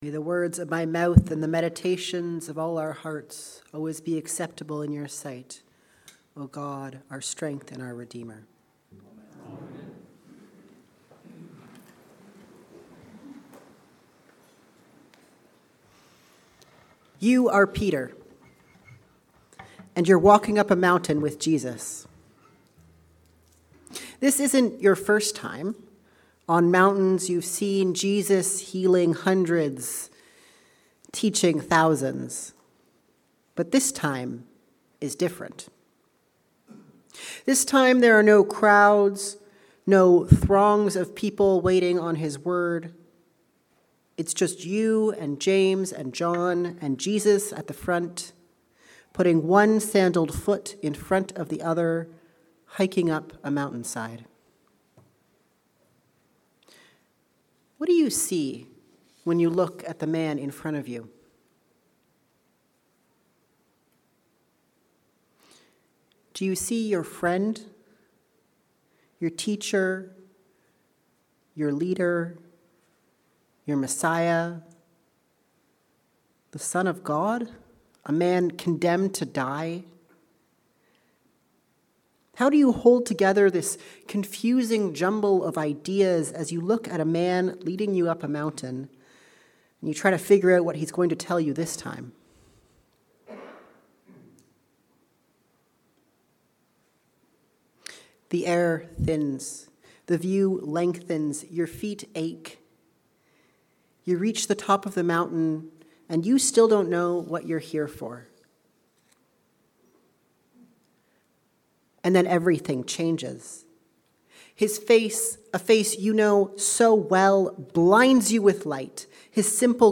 You are Peter. A Sermon for the Transfiguration